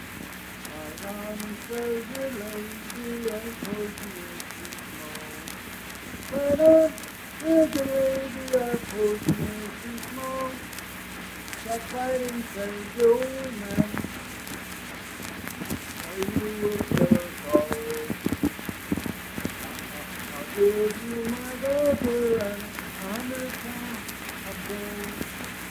Unaccompanied vocal music performance
Verse-refrain 2(4).
Voice (sung)